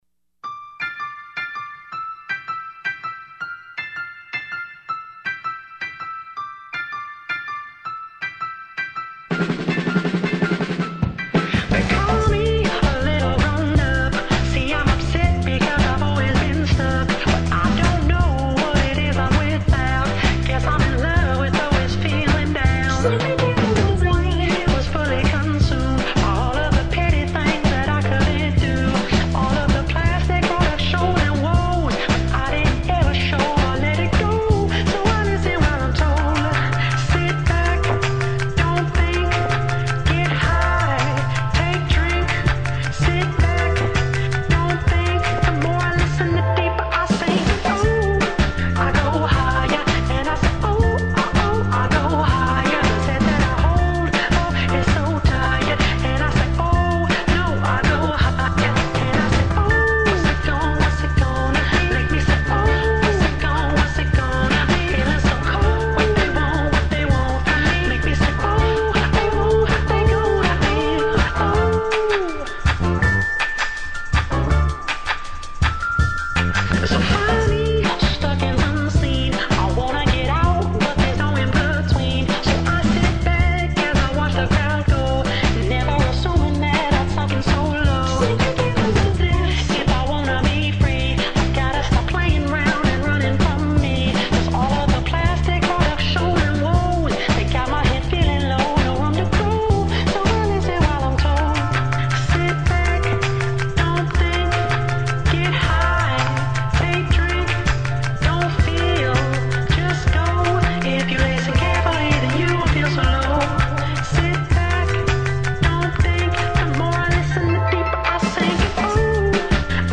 Regardless… super fun and catchy jam.